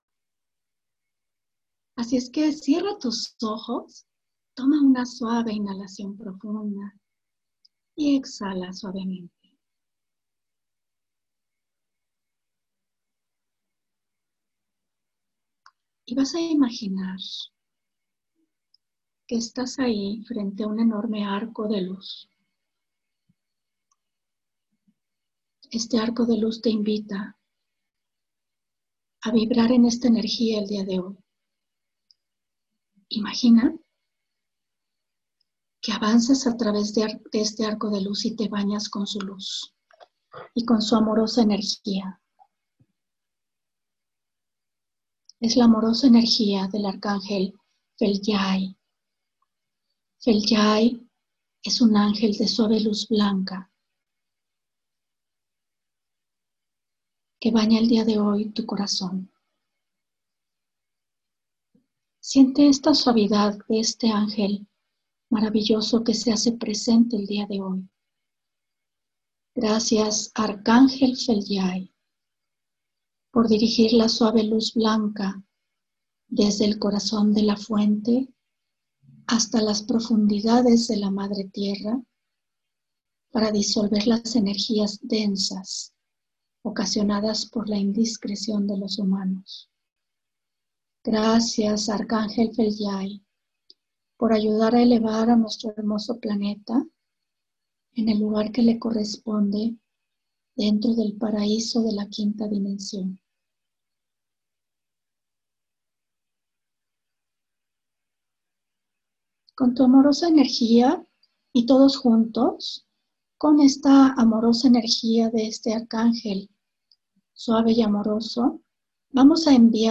meditacion-arcangel-fhelyai.m4a